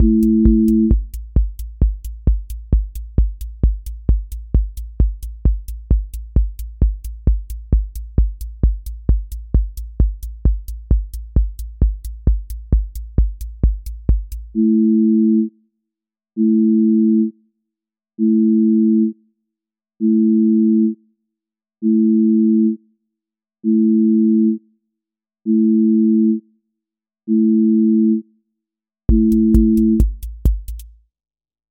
QA Listening Test trance Template: trance_euphoria
euphoric trance build and drop with pumping offbeat bass, breakdown pads, and a bright supersaw lift
• voice_kick_808
• voice_hat_rimshot